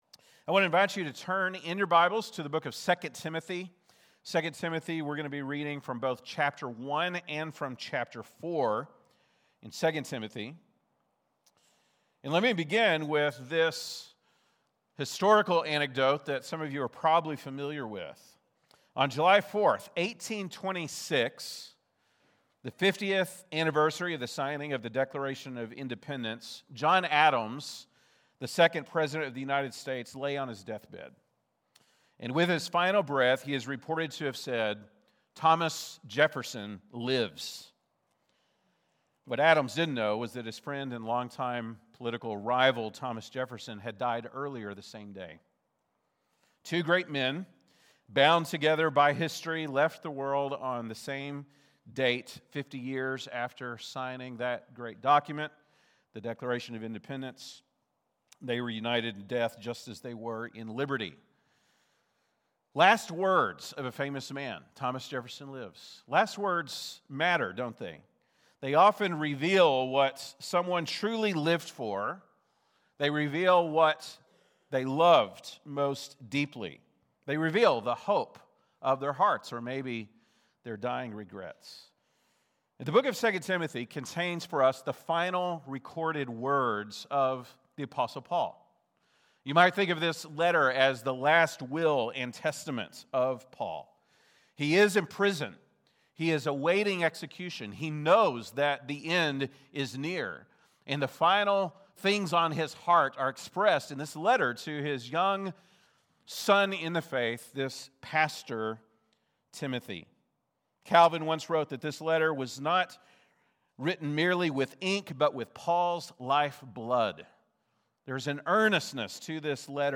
December 28, 2025 (Sunday Morning)